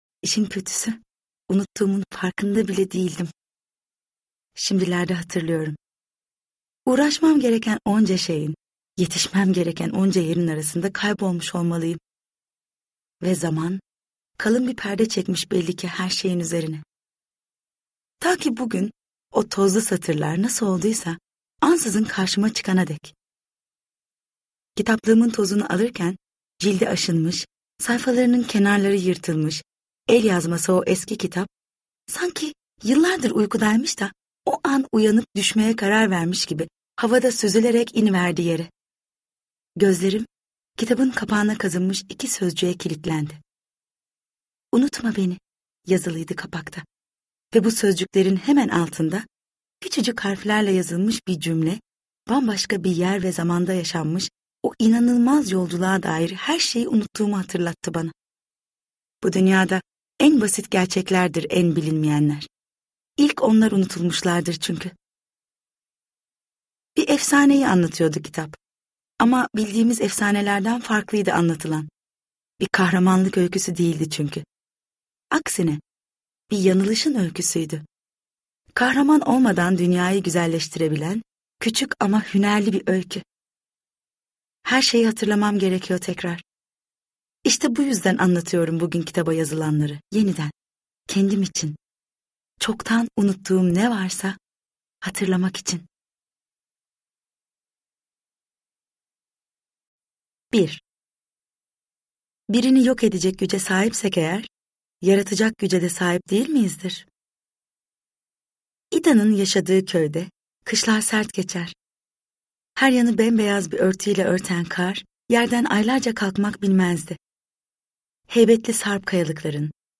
Unutma Beni’yi Sevinç Erbulak’ın seslendirmesiyle dinleyebilirsiniz.